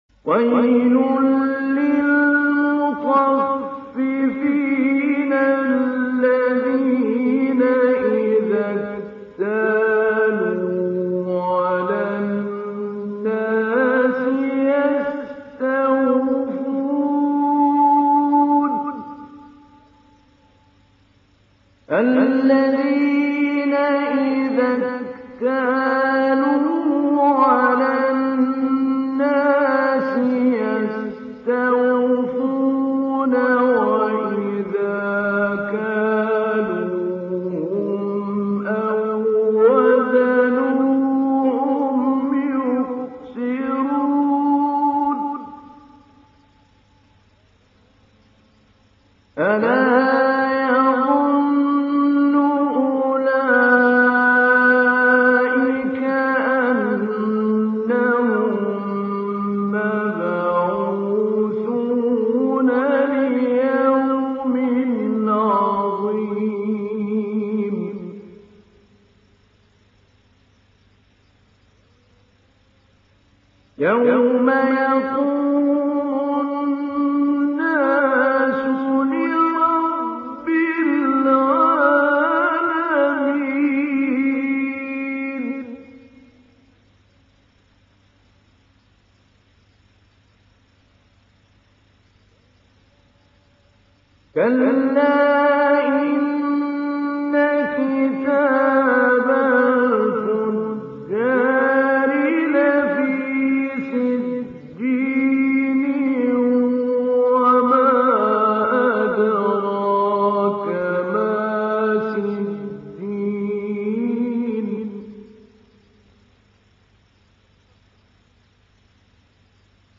Télécharger Sourate Al Mutaffifin Mahmoud Ali Albanna Mujawwad